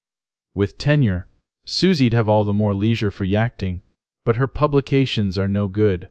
speech-style-transfer text-to-speech voice-cloning